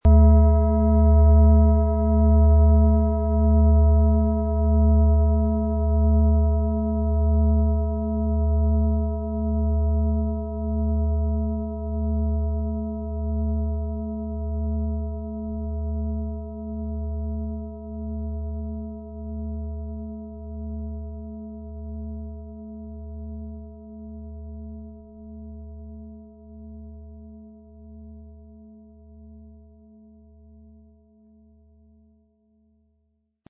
Beim Speichern des Tones der Schale haben wir sie angespielt, um herauszubekommen, welche Körperregionen sie zum Schwingen bringen könnte.
Im Audio-Player - Jetzt reinhören hören Sie genau den Original-Ton der angebotenen Schale.
MaterialBronze